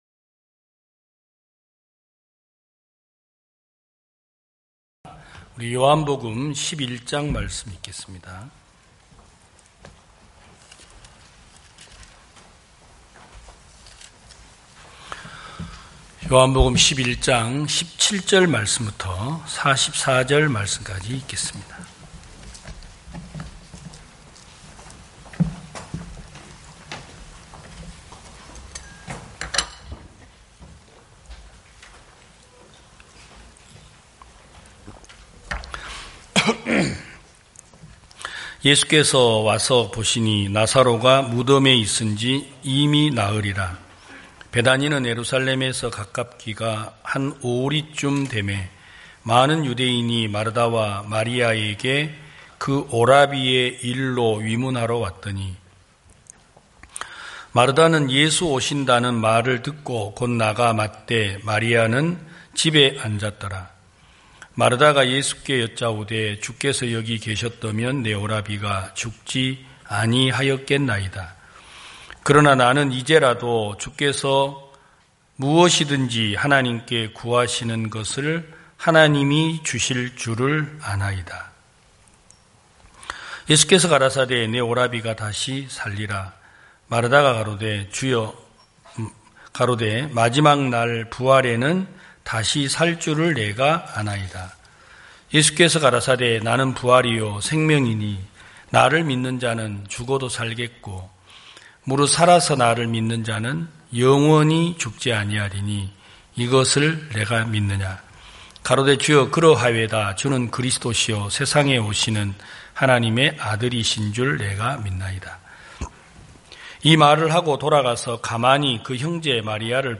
2022년 05월 22일 기쁜소식부산대연교회 주일오전예배
성도들이 모두 교회에 모여 말씀을 듣는 주일 예배의 설교는, 한 주간 우리 마음을 채웠던 생각을 내려두고 하나님의 말씀으로 가득 채우는 시간입니다.